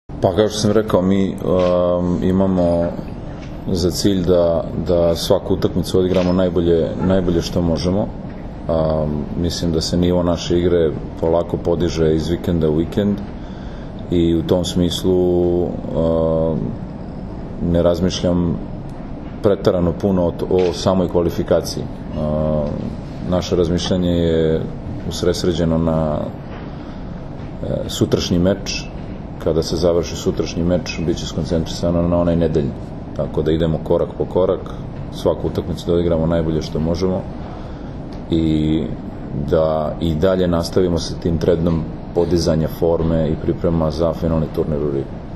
U novosadskom hotelu “Sole mio” danas je održana konferencija za novinare, kojoj su prisustvovali Dragan Stanković, Nikola Grbić, Dragan Travica i Mauro Beruto, kapiteni i treneri Srbije i Italije.
IZJAVA NIKOLE GRBIĆA